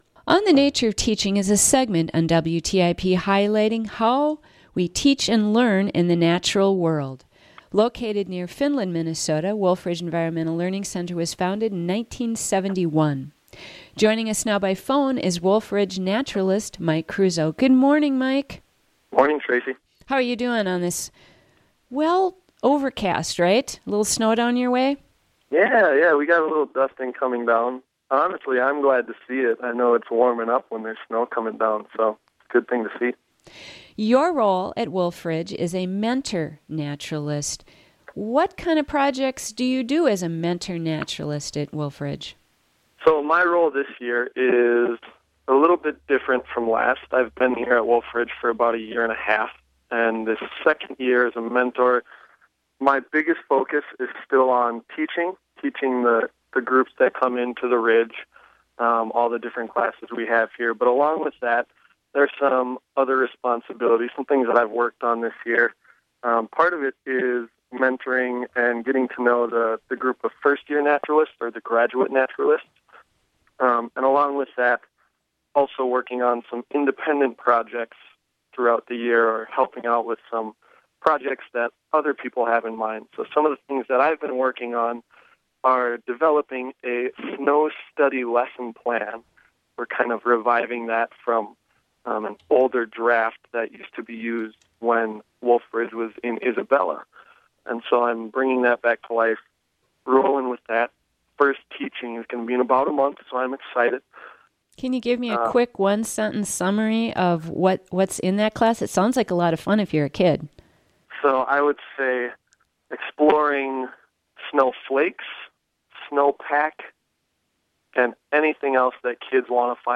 On the Nature of Teaching is a monthly segment on WTIP highlighting how we teach and learn in the natural world.